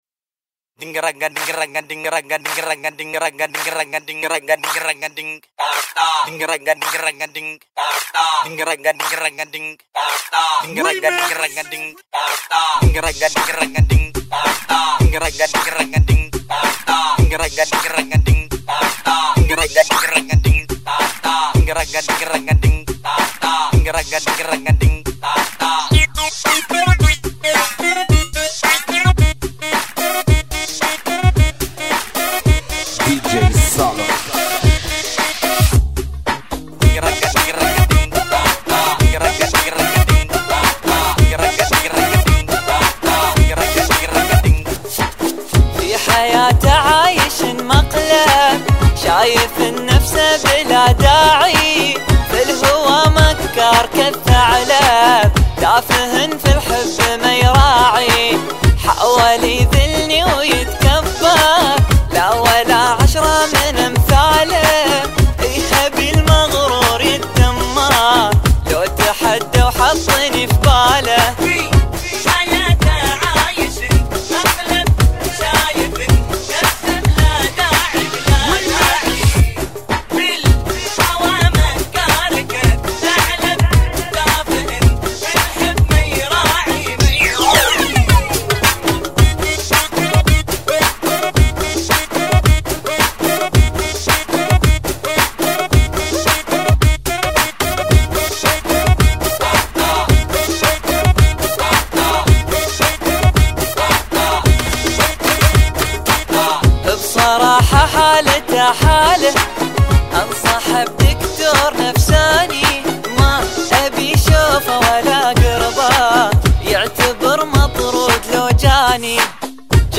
ريميكس